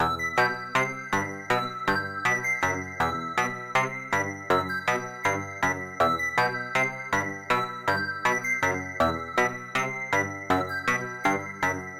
描述：喜欢这个合成器
标签： 126 bpm Hip Hop Loops Synth Loops 2.56 MB wav Key : Unknown FL Studio
声道立体声